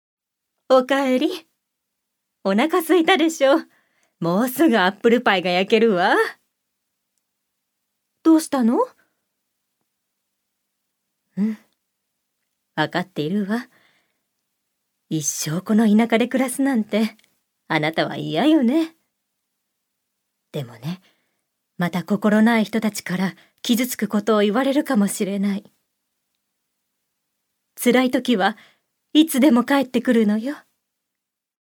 女性タレント
セリフ５